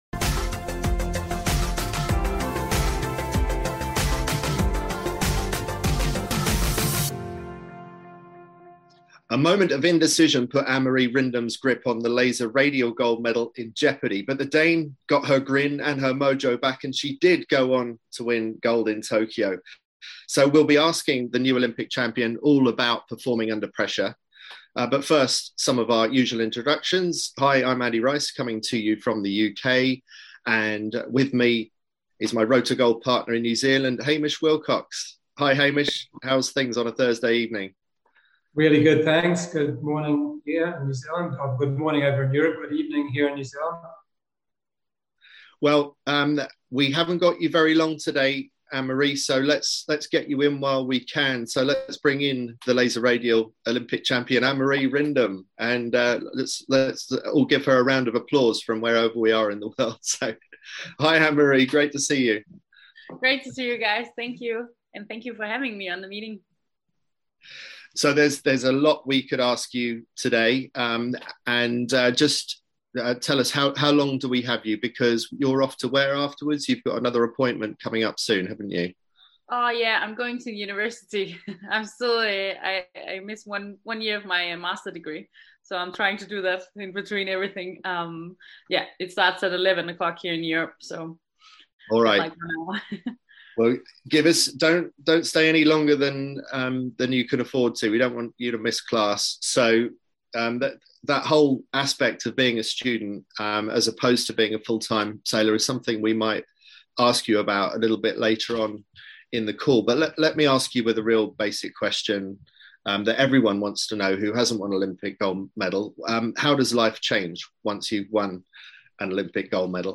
Our weekly Q&A sessions on Zoom, to answer your burning questions and enlighten you on your Road To Gold